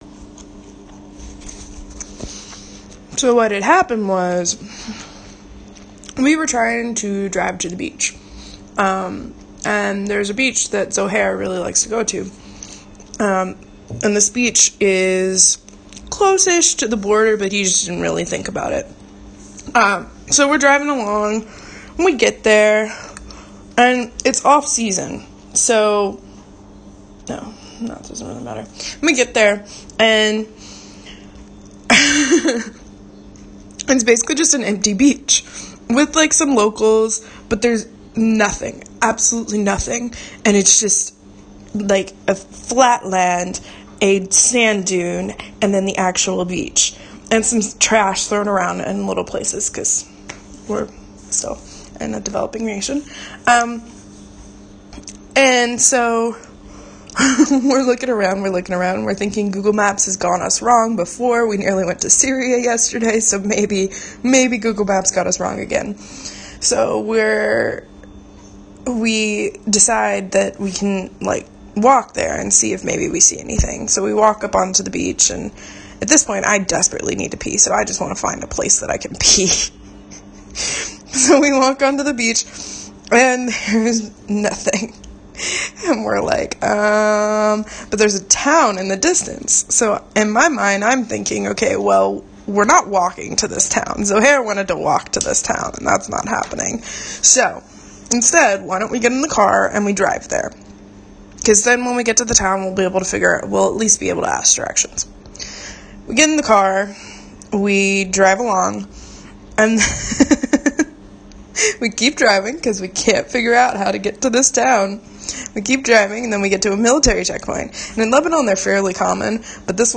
I thought ya’ll deserved the full story: (PLUS a bonus audio retelling of this story [complete with lots of nervous laughter], because the only way I could figure out how to audibly tell it was to tell it to myself – though, warning, it was really hard to explain this story so it isn’t my best quality).